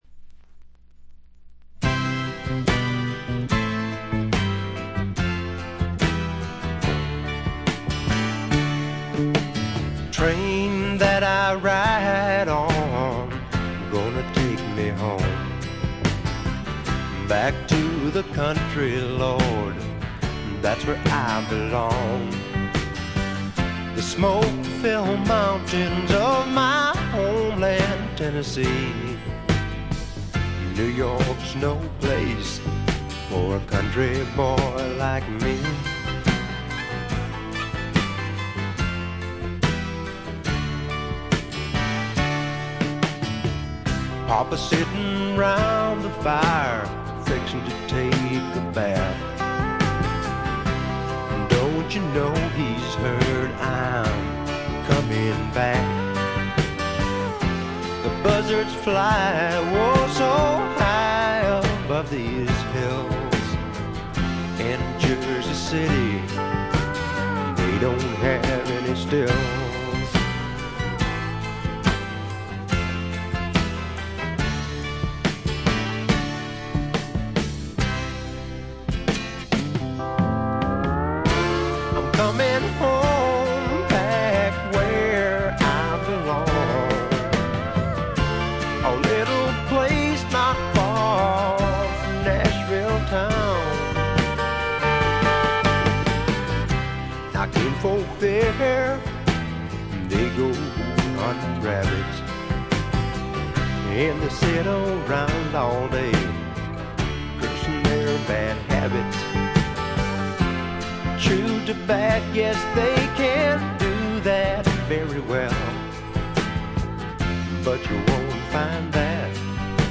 静音部でチリプチが聴かれますがおおむね良好に鑑賞できると思います。
試聴曲は現品からの取り込み音源です。